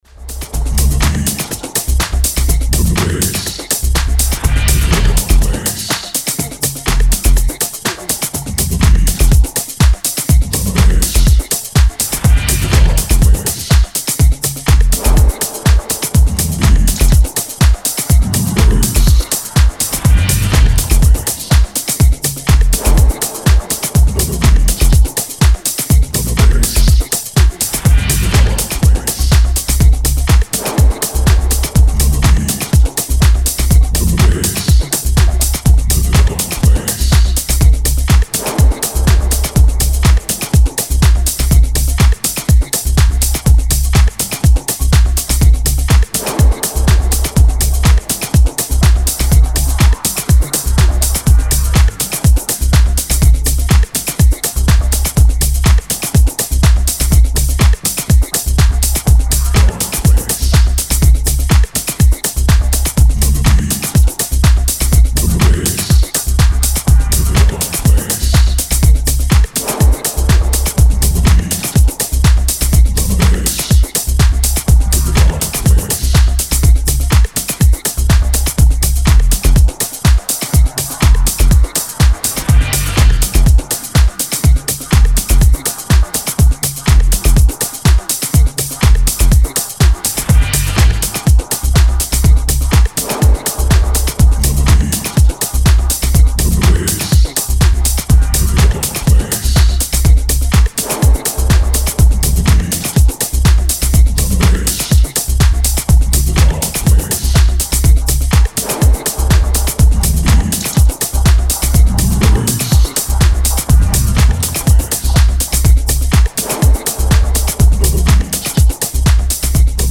Style: Techno / Groove / Acid